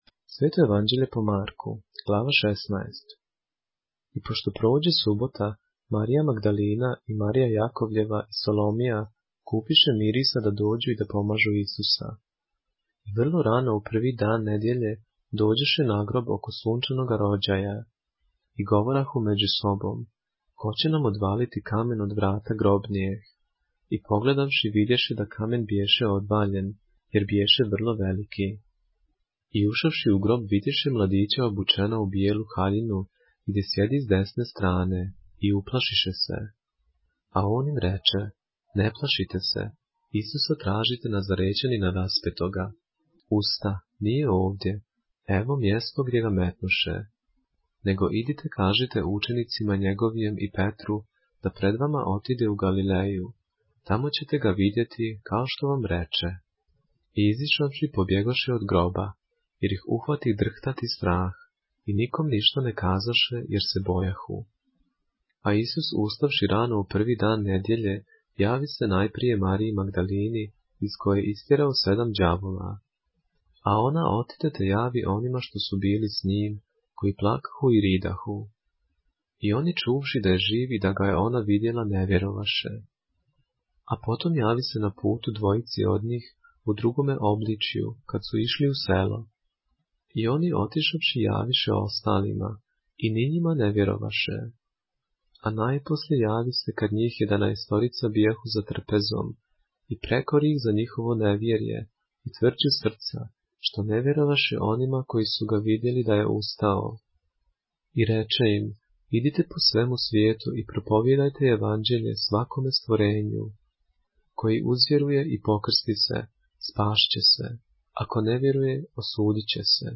поглавље српске Библије - са аудио нарације - Mark, chapter 16 of the Holy Bible in the Serbian language